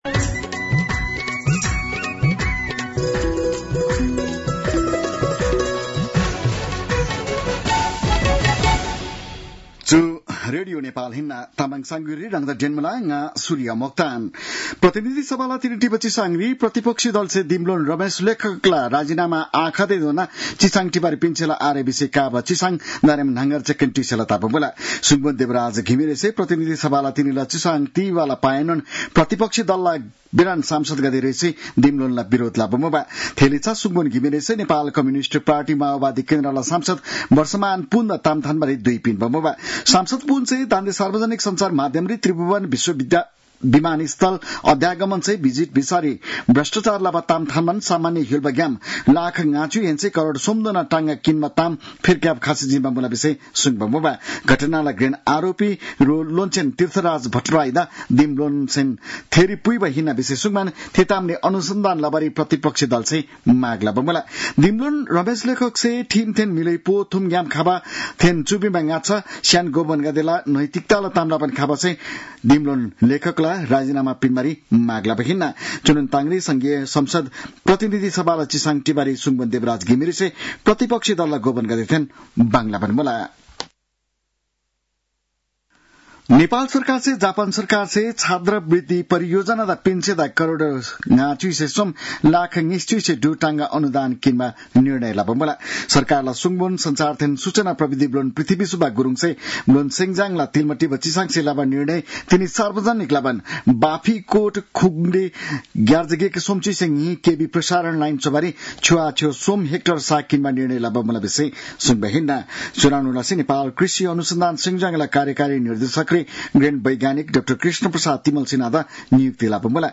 तामाङ भाषाको समाचार : १३ जेठ , २०८२